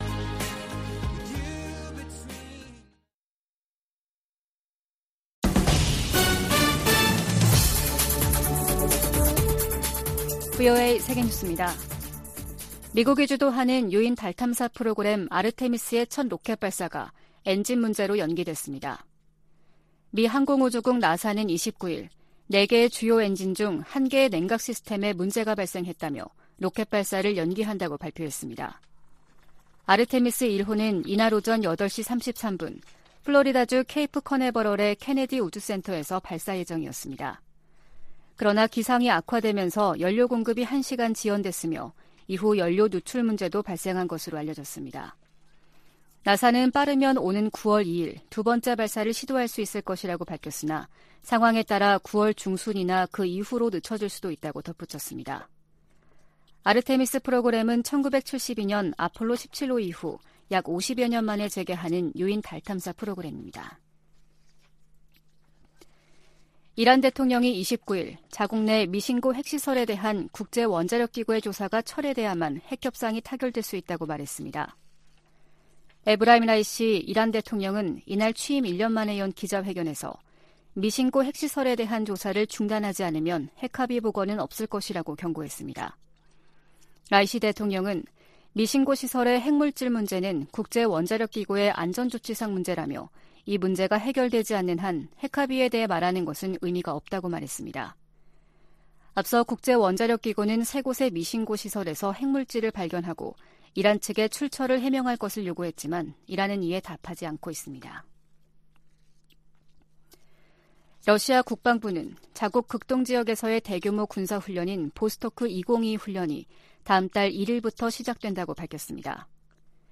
VOA 한국어 아침 뉴스 프로그램 '워싱턴 뉴스 광장' 2022년 8월 30일 방송입니다. 북한이 핵실험 준비를 마치고 한국을 향해 보복성 대응을 언급하고 있다고 한국 국방부 장관이 말했습니다. 제10차 핵확산금지조약 (NPT) 평가회의가 러시아의 반대로 최종 선언문을 채택하지 못한 채 끝났습니다.